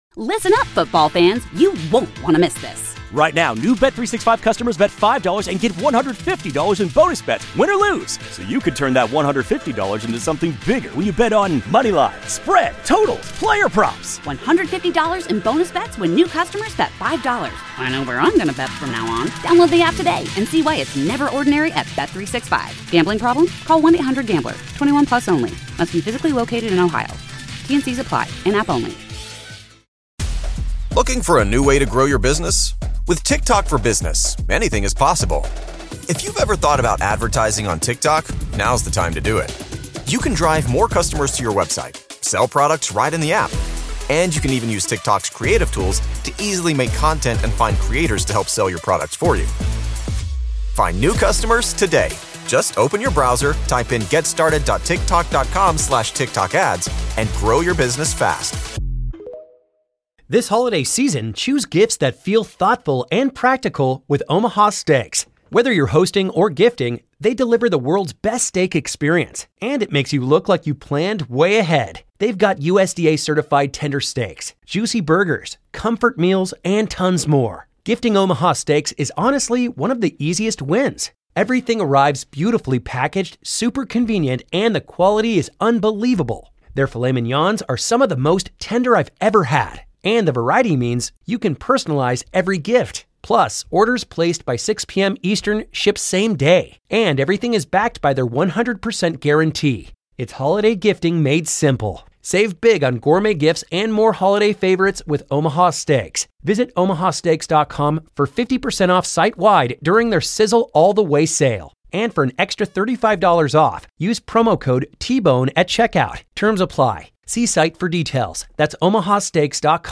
1 Interview With "The Choral" Star Ralph Fiennes & Director Nicholas Hytner 12:33